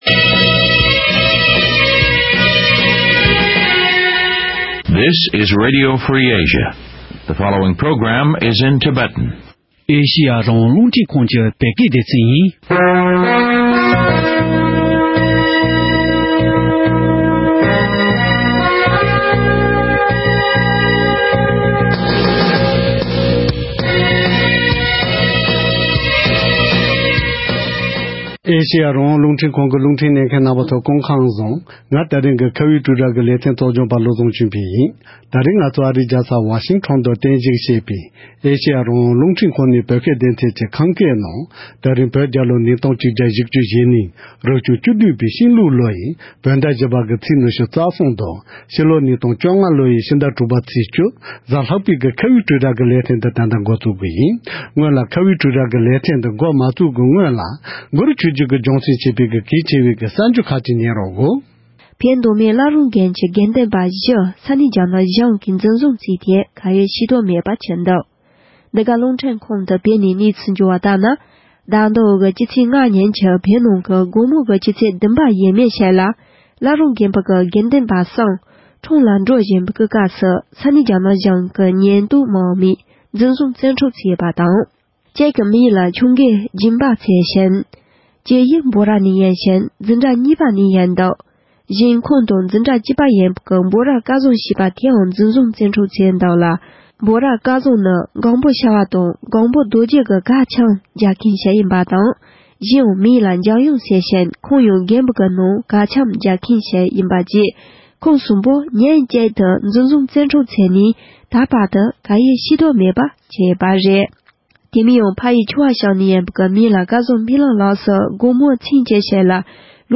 ༄༅། །ཐེངས་འདིར་ང་ཚོའི་ཁ་བའི་གྲོས་རྭ་ཞེས་པའི་ལེ་ཚན་འདིའི་ནང་ཕ་རེན་སེ་ནང་ཡོད་པའི་བོད་རིགས་སྤྱི་མཐུན་ཚོགས་པས་གཙོས་པའི་ཚོགས་པ་བཞིས་མཉམ་འབྲེལ་གྱི་ཐོག་ནས་ཉེ་ཆར་བོད་བསྟན་སྲིད་དང་མི་རིགས་དང་བཅས་པའི་ཆེད་དུ་རང་ལུས་མེར་བསྲེགས་གཏོང་མཁན་བོད་མི་༡༤༡་ལ་གདུང་སེམས་མཉམ་སྐྱེད་ཀྱི་ངོ་རྒོལ་གྱི་ལས་འགུལ་ཞིག་སྤེལ་ཡོད་པ་སྐོར་ཚོགས་པ་བཞིའི་འབྲེལ་ཡོད་མི་སྣ་ཁག་གི་མཉམ་དུ་བགྲོ་གླེང་མོལ་ཞུས་པར་གསན་རོགས་གནང་།